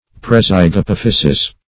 Search Result for " prezygapophyses" : The Collaborative International Dictionary of English v.0.48: Prezygapophysis \Pre*zyg`a*poph"y*sis\, n.; pl. Prezygapophyses .
prezygapophyses.mp3